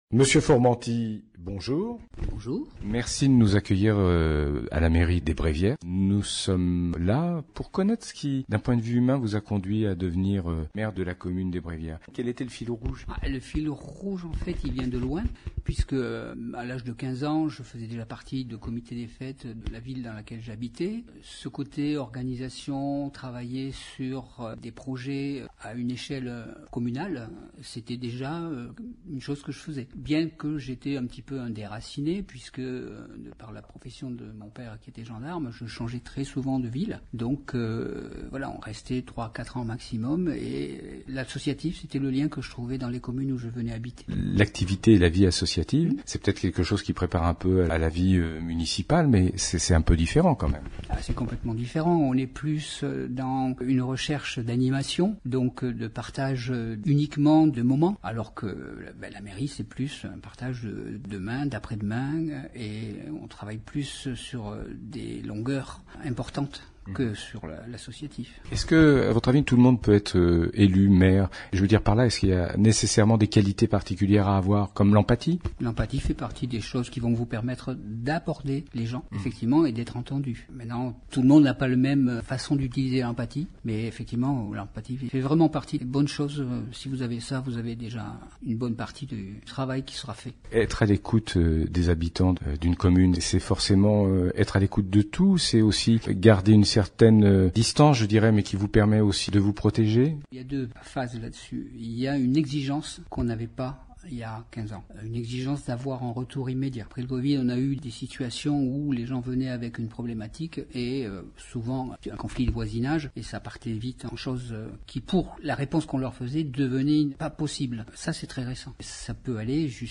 Interview de Monsieur Formenty Maire des Bréviaires - Radio RVE